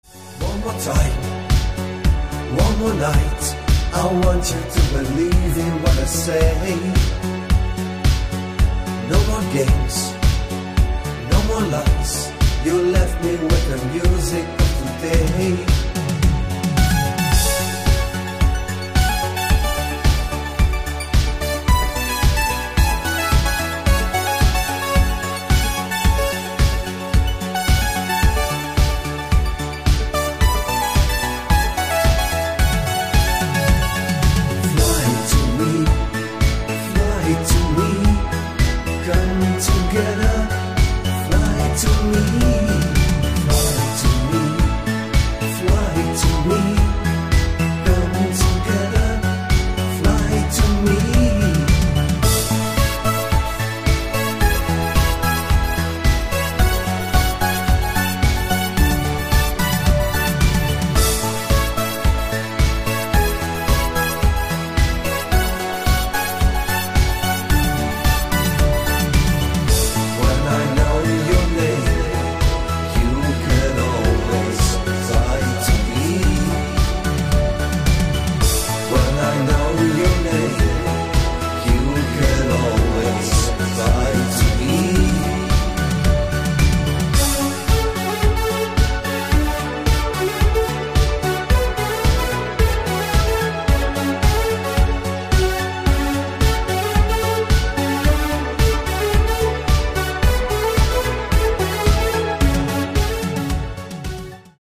• Качество: 320, Stereo
мужской вокал
Italo Disco
электронная музыка
спокойные
eurobeat